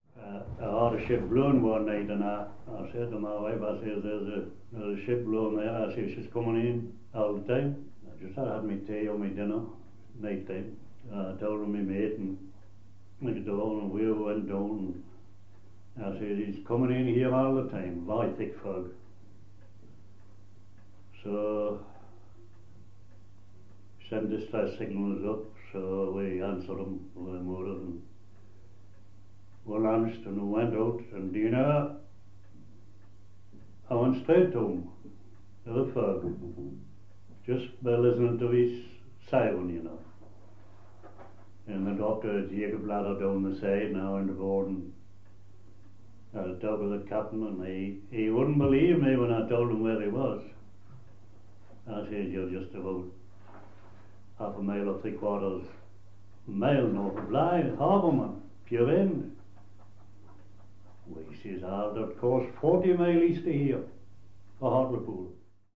These sound files are extracts (short edited pieces) from longer oral history interviews that Northumberland Archives recorded.